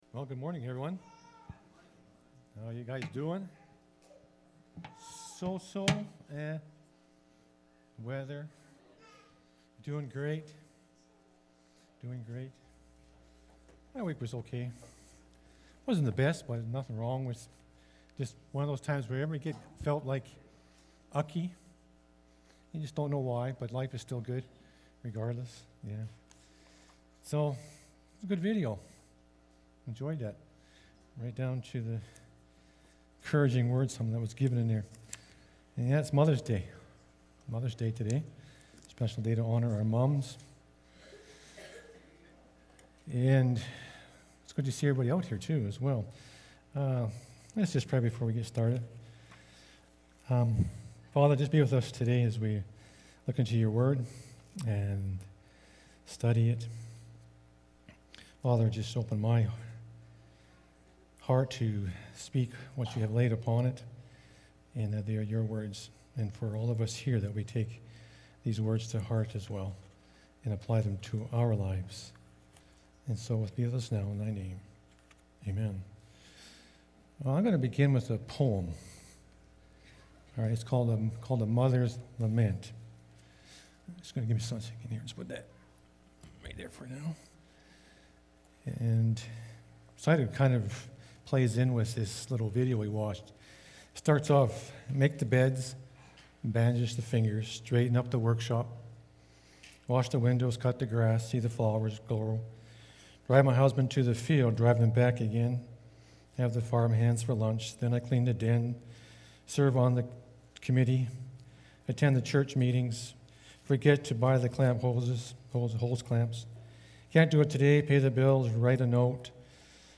May-8-2022-sermon-audio.mp3